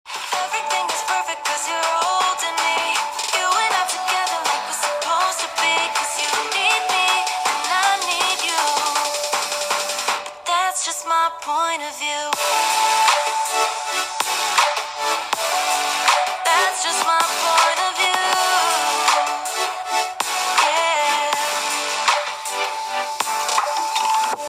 arrows Nは、モノラルスピーカーを搭載。
肝心の音については、"普通"な印象です。（※Dolby Atmosをオン時の評価）
▼arrows Nのモノラルスピーカーの音はこちら！
Dolby Atmosオフ時の音は、正直イマイチです。